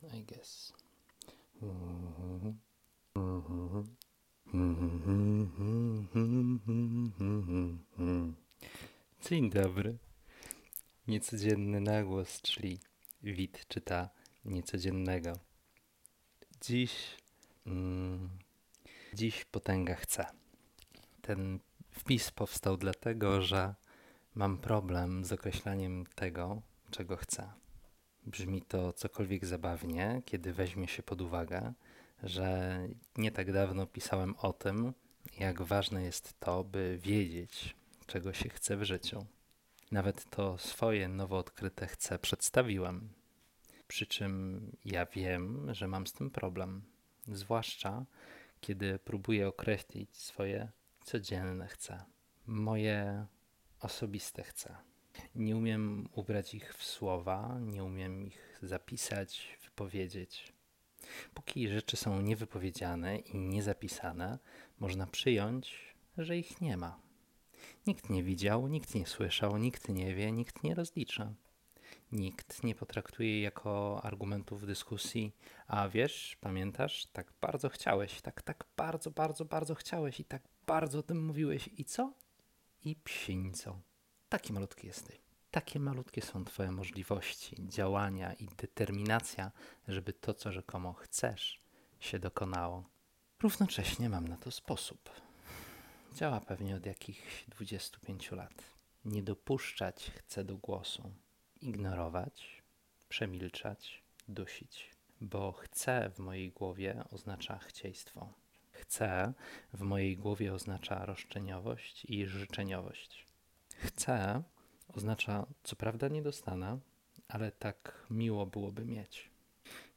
Na głos: Potęga chcę